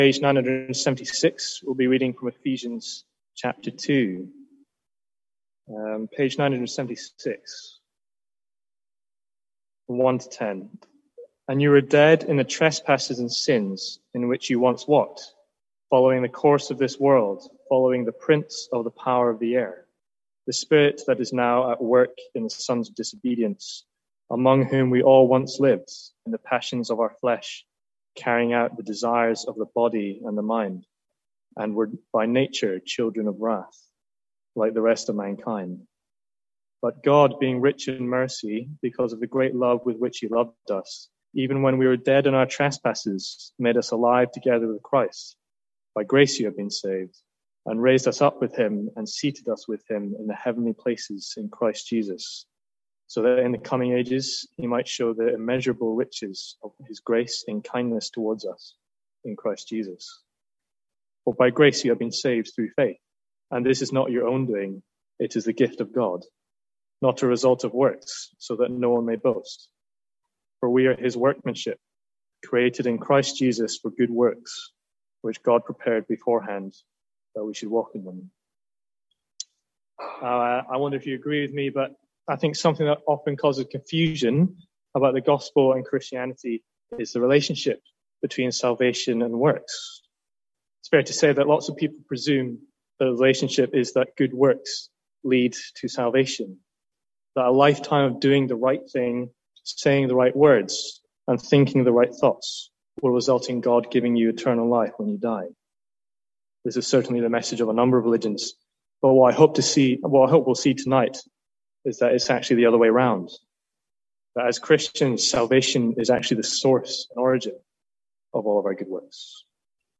Sermons | St Andrews Free Church
From our evening series looking at 'Calendar Verses'